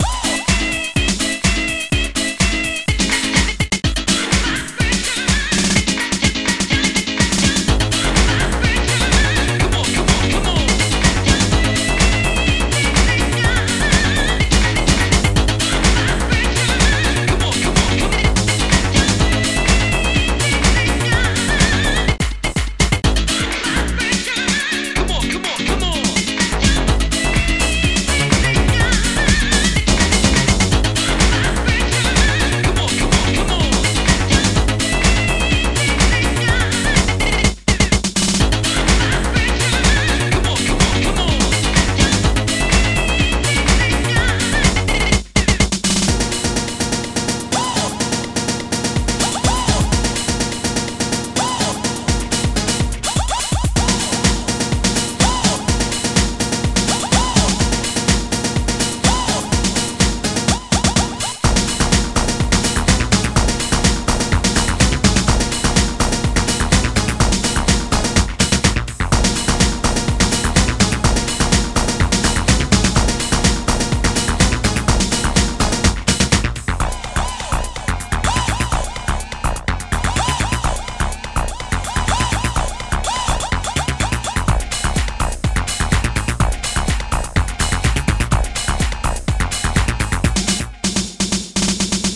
9-30-power-hihat1
mone-snaredrum1
d-synth-c